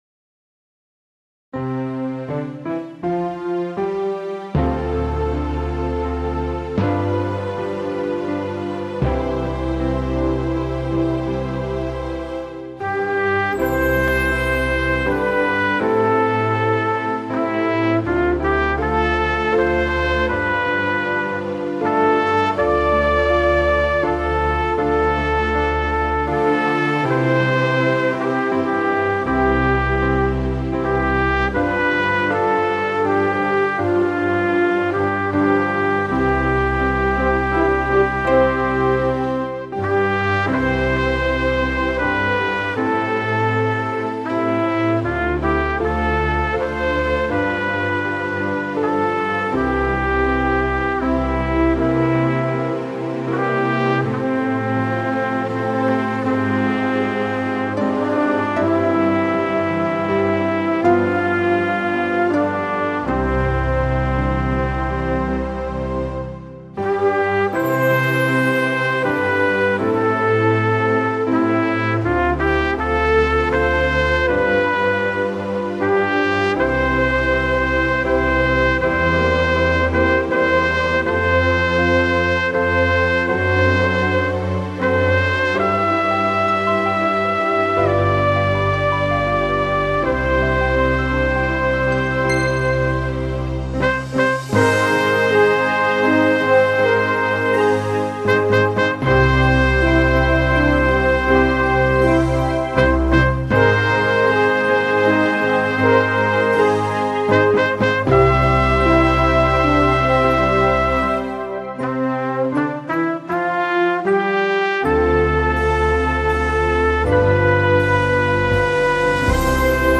FDHS School Song (Instrumental) O Douglass High, thy name we acclaim.